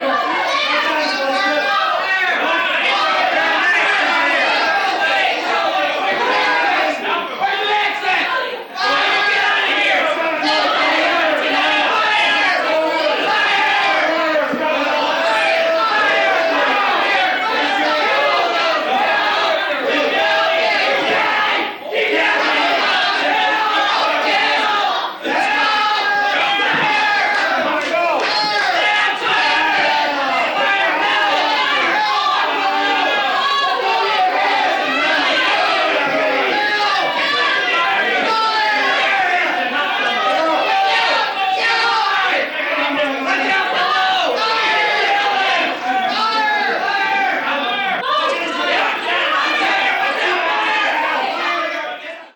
Звуки паники и крики среди пламени горящего здания